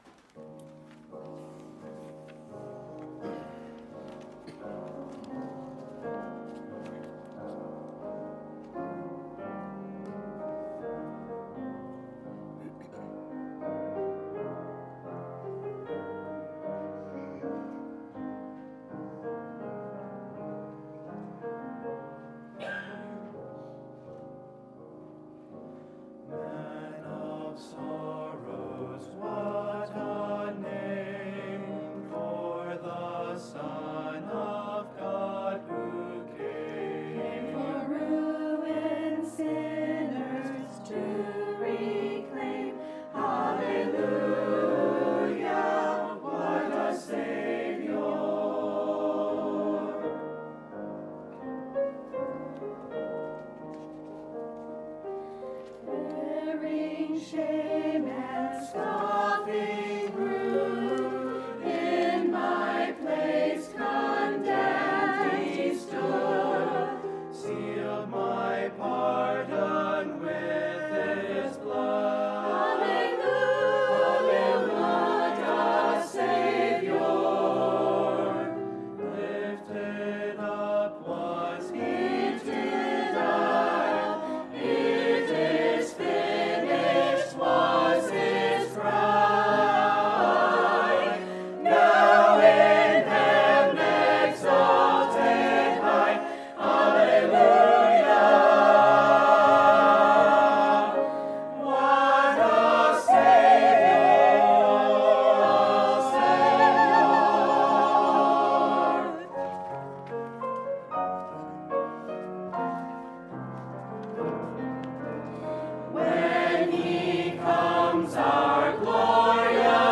Special Easter Music Service & Message
Sunday, April 1, 2018 – Sunday Morning Service
Sermon: The Power of His Ressurection